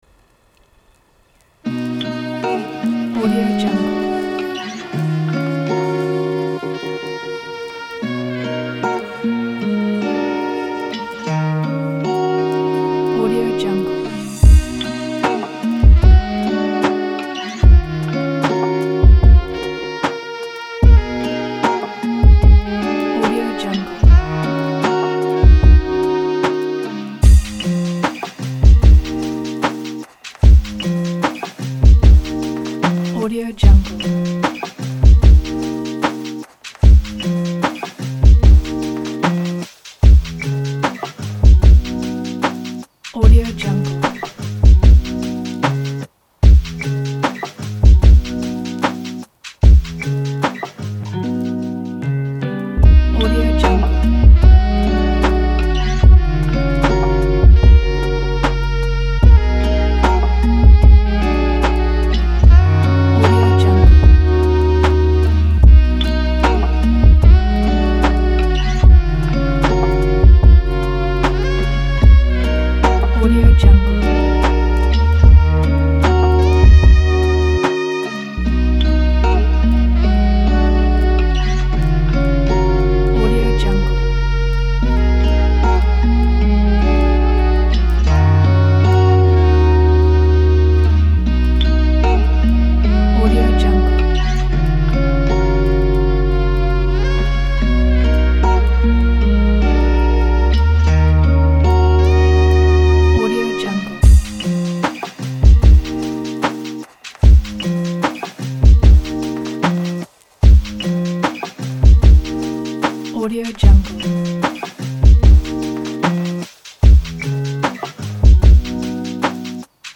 آمبیانس و آرام